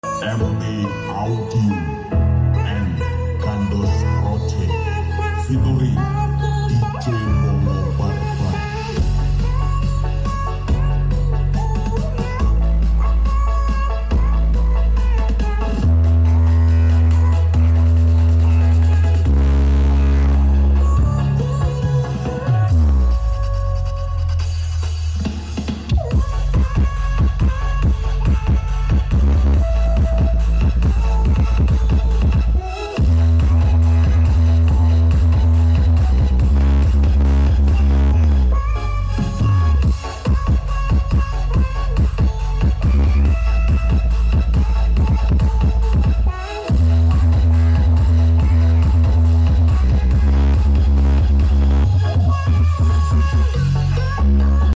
Blizzard audio ceksound karnaval Senggreng